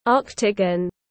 Hình bát giác tiếng anh gọi là octagon, phiên âm tiếng anh đọc là /’ɔktəgən/.
Octagon /’ɔktəgən/